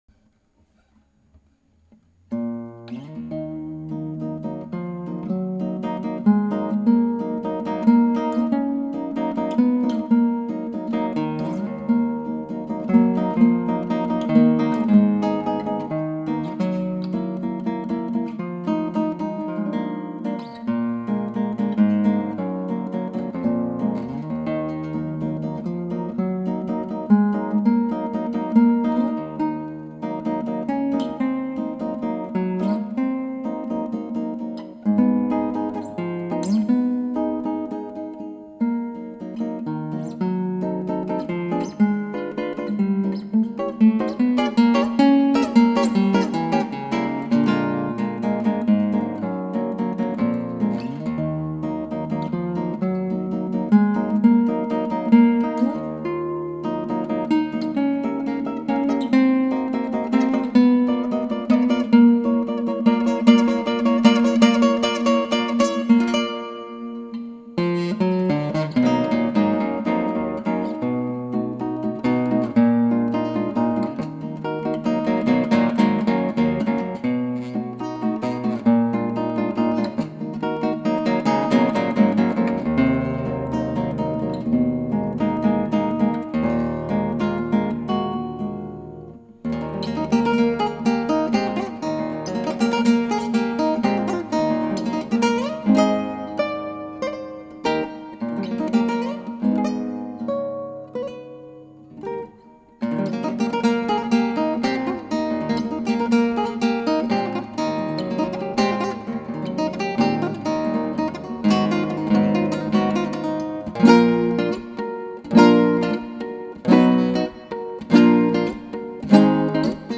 クラシックギター　ストリーミング　コンサート
「朗々と・・」　編
だからこの曲書いたという話ですが 確かにチェロッぽいでつ
でも録音ではそのヴィブラート、低音は変化ひろいにくいデス。
ところでそのアンプ、実は真空管使ってるんです。
で、この曲ではヴィブラートの練習ばっかやってるから中間部がこなせてないんです。